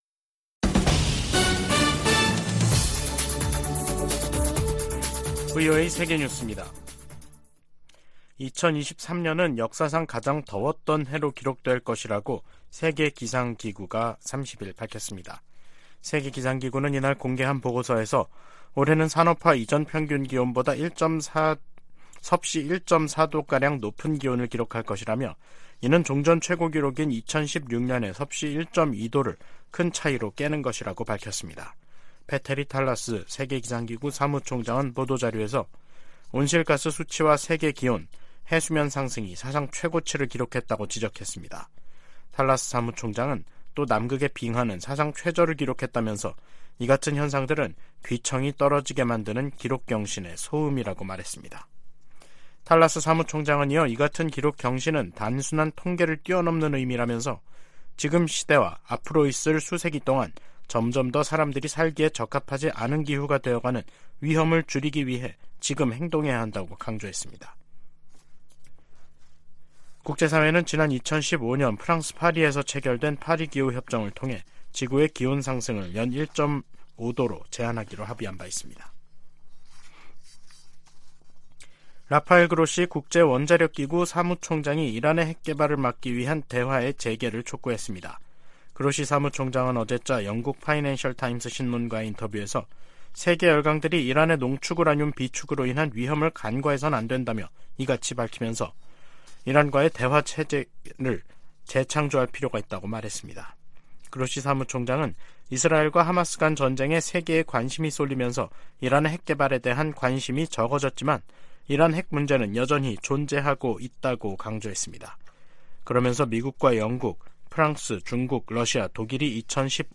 VOA 한국어 간판 뉴스 프로그램 '뉴스 투데이', 2023년 11월 30일 3부 방송입니다. 미국 재무부 해외자산통제실이 북한의 해킹 자금을 세탁한 가상화폐 믹서 업체 '신바드'를 제재했습니다. 북한이 제도권 금융기관에서 암호화폐 인프라로 공격 대상을 변경했다고 백악관 고위 관리가 밝혔습니다. 북한-러시아 군사 협력에 제재를 부과하도록 하는 법안이 미 하원 외교위원회를 만장일치 통과했습니다.